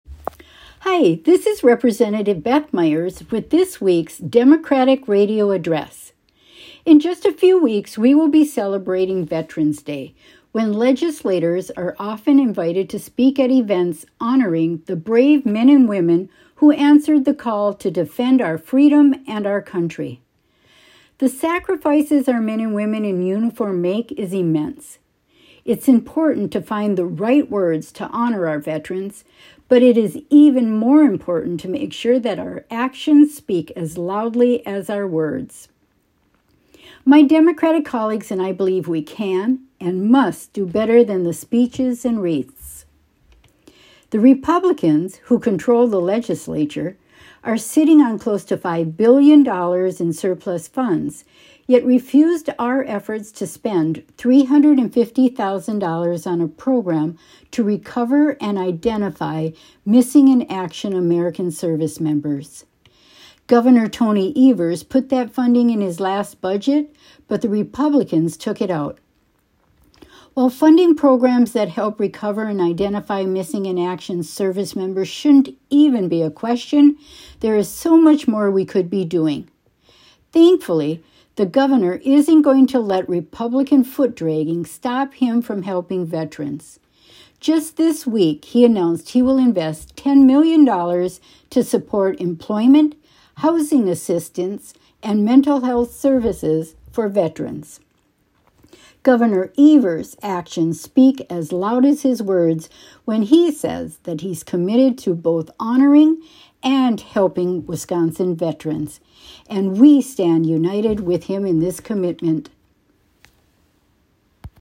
Weekly Dem radio address: Rep. Meyers touts Gov. Evers spending $10 million to support employment, housing assistance and mental health services for veterans - WisPolitics